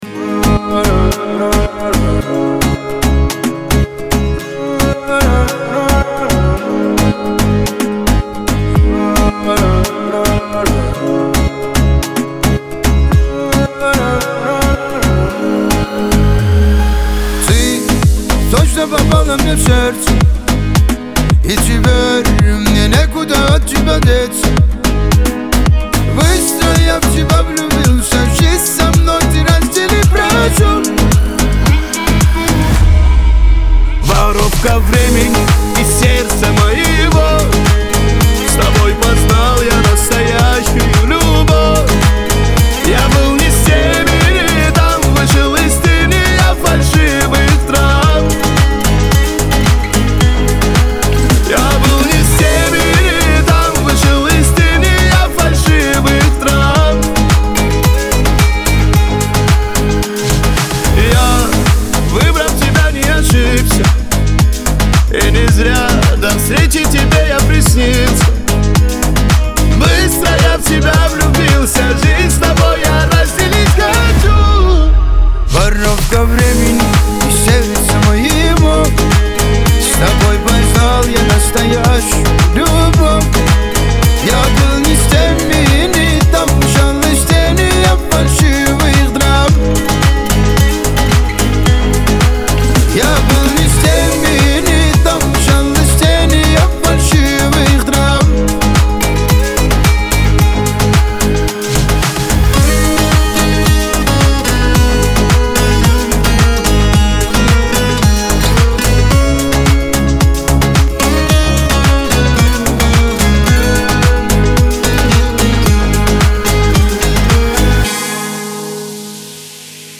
Лирика
грусть
Кавказ – поп , дуэт